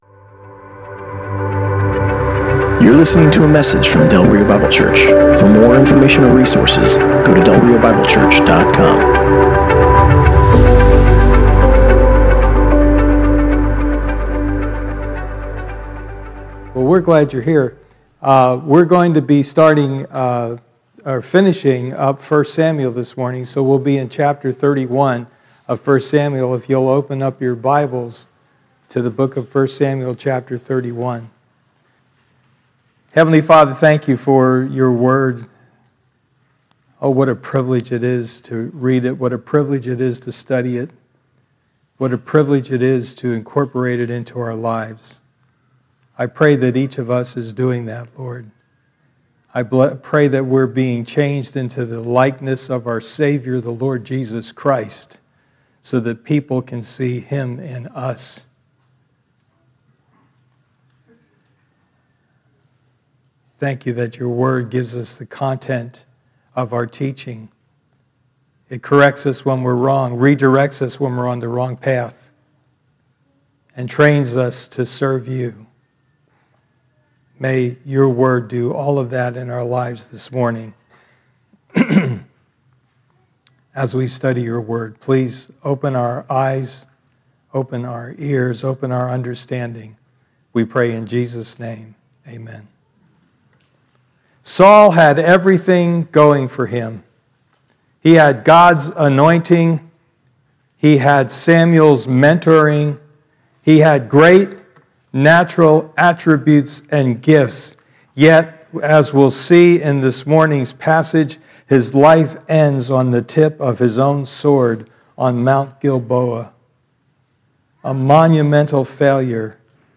Passage: 1 Samuel 31: 1-31 Service Type: Sunday Morning